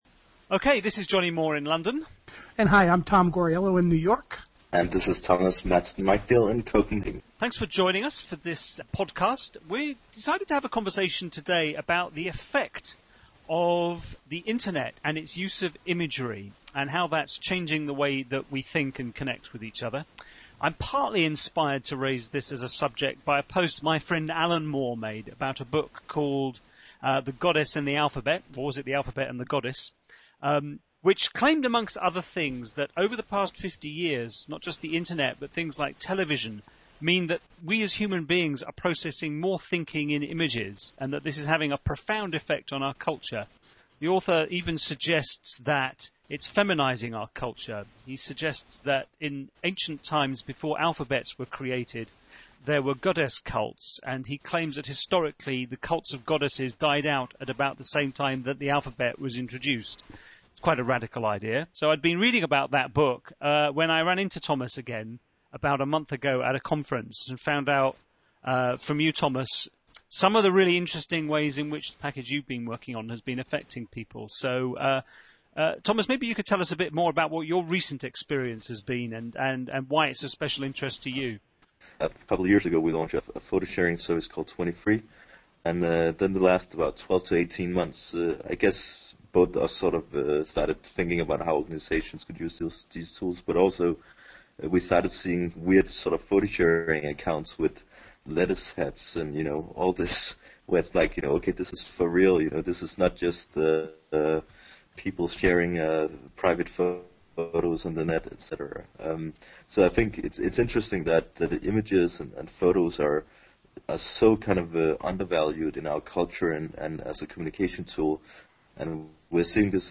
Download the Podcast – 24m – MP3 (8.3 MB) Podcast RSS feed for iPodder etc. It was a suitably non-linear conversation.